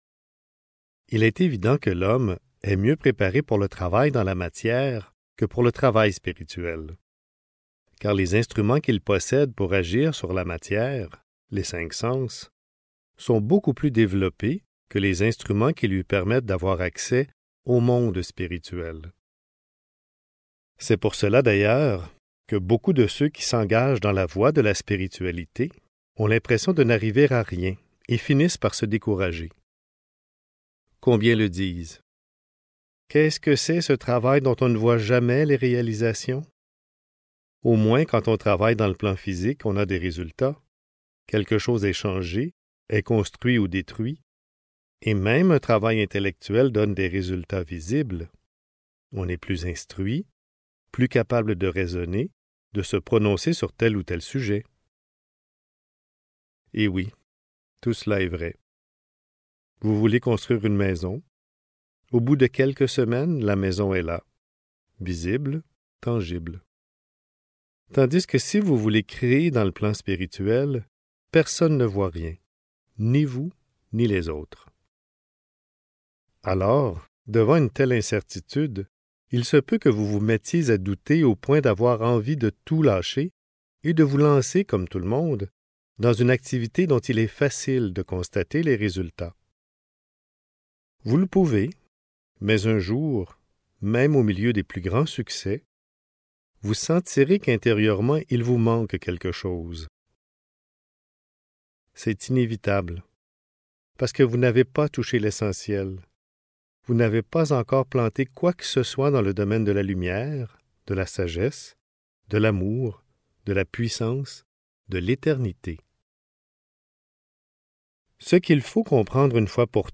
Puissances de la pensée (Livre audio | CD MP3) | Omraam Mikhaël Aïvanhov